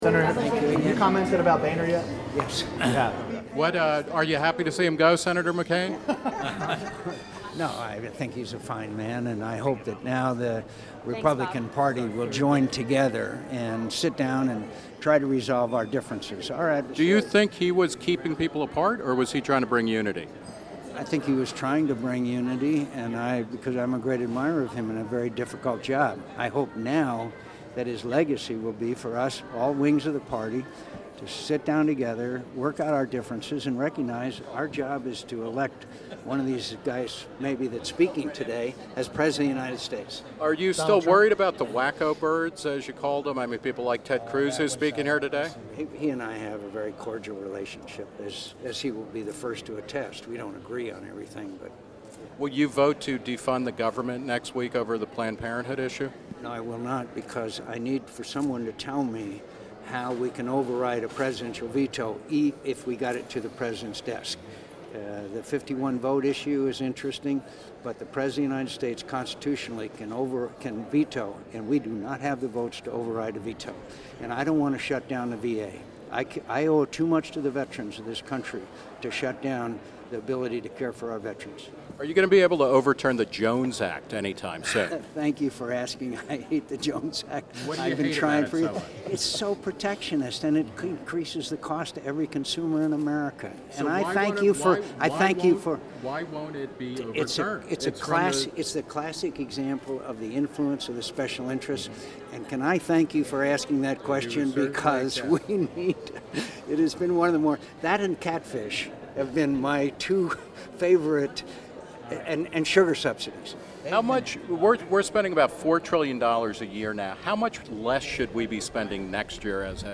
Reason TV met up with Sen. John McCain (R-Az.) outside the Values Voters Summit in Washington, D.C.